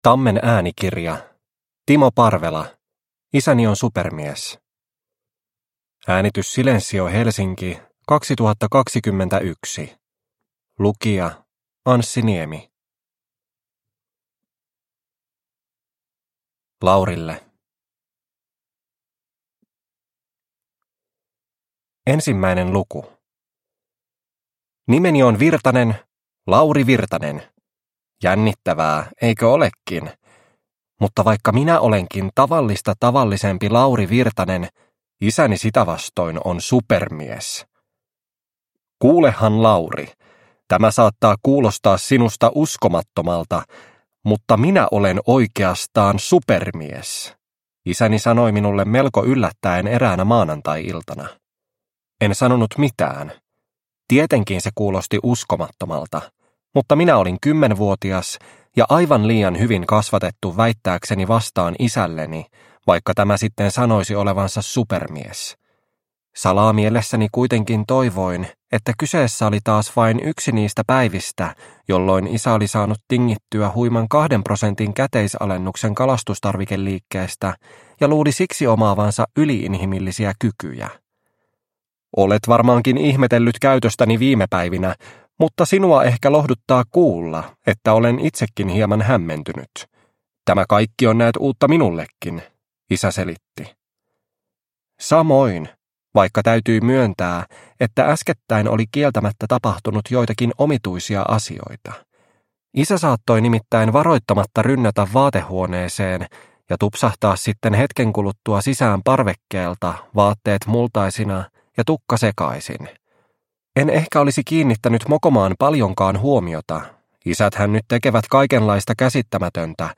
Isäni on supermies – Ljudbok – Laddas ner